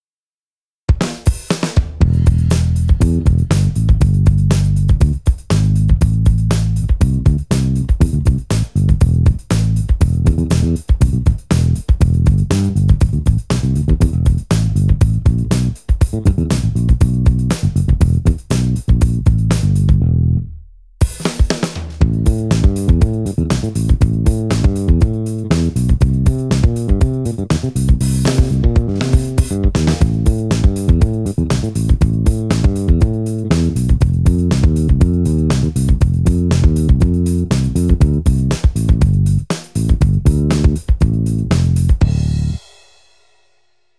Erst gestern habe ich am Keyboard eine Funk-Bassline mit dem "Royal" erstellt (den habe ich mir gerade gegönnt und teste ihn noch aus).
Hier mal ein Beispiel mit den unbearbeiteten "Rohsignalen" aus den Plugins (Drummer "Solid" und Bassist "Royal"). Lediglich in der Summe ist eine leichte Komprimierung drauf, um das Signal in der Lautstärke anzuheben.